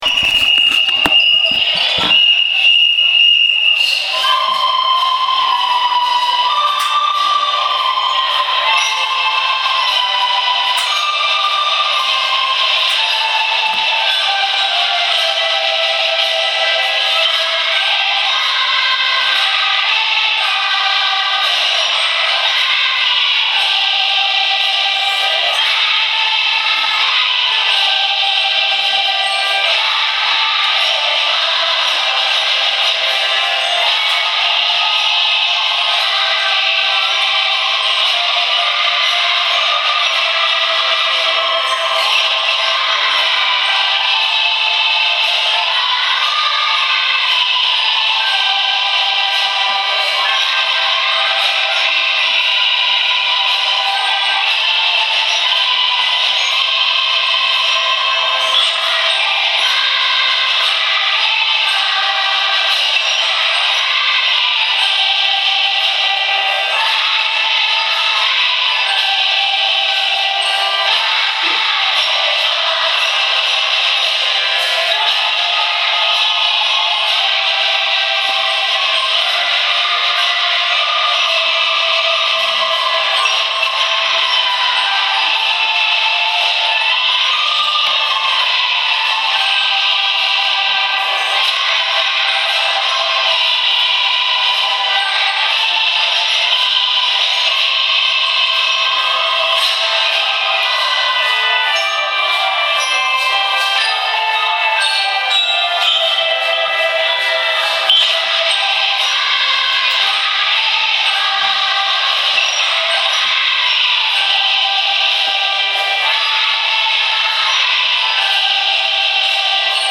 音楽集会を行いました
２月１５日 今日の児童集会は、音楽集会です。 『さんぽ』の曲を、１・２年生は歌、３〜５年生はリコーダー、６年生は合奏でそれぞれ聞きあった後、みんなで合わせました。